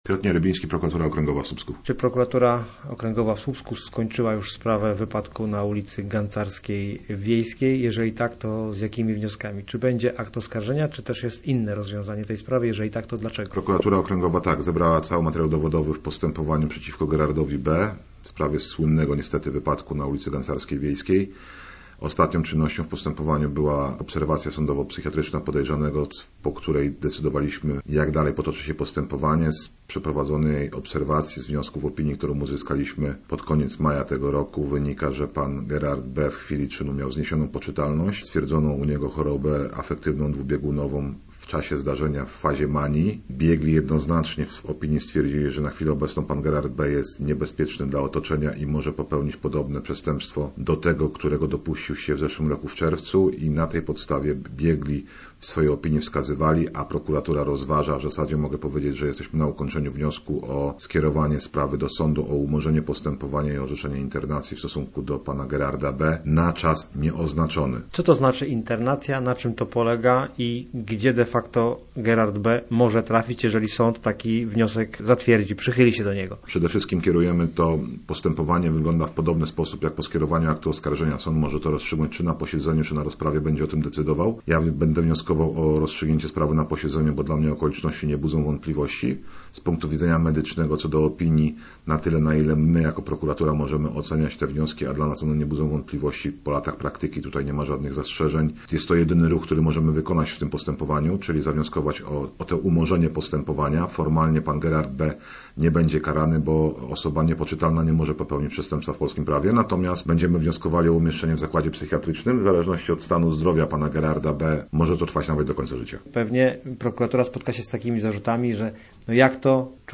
Posłuchaj rozmowy naszego reportera